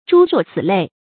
諸若此類 注音： ㄓㄨ ㄖㄨㄛˋ ㄘㄧˇ ㄌㄟˋ 讀音讀法： 意思解釋： 同「諸如此類」。